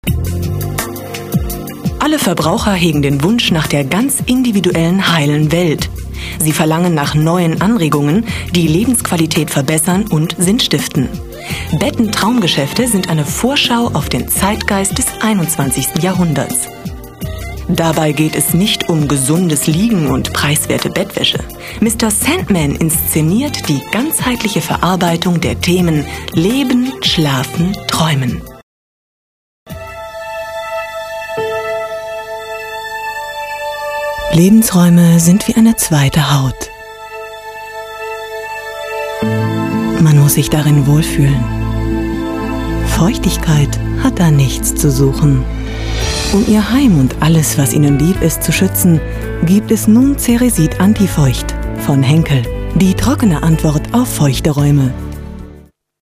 Profi-Sprecherin deutsch, Werbesprecherin.
Kein Dialekt
Sprechprobe: Sonstiges (Muttersprache):
female voice over artist german.